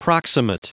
Prononciation du mot proximate en anglais (fichier audio)
Prononciation du mot : proximate